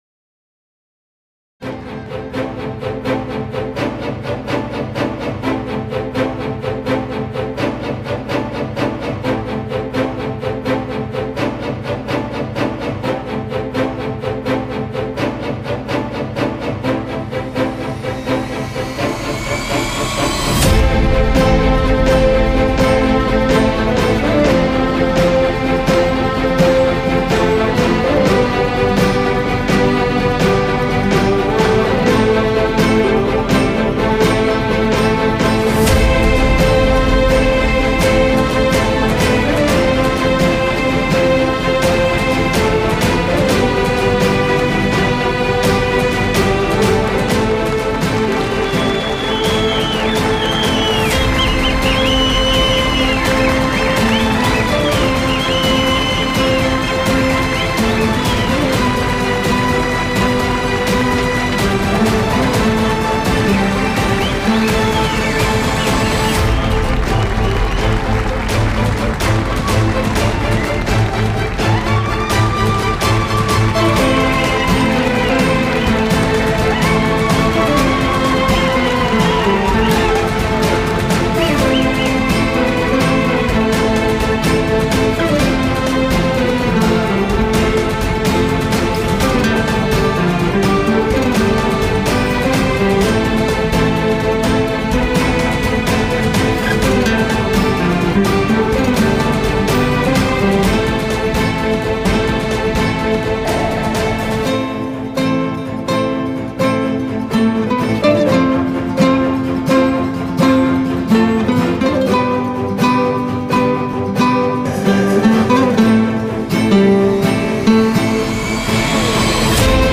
tema dizi müziği, duygusal mutlu heyecan fon müziği.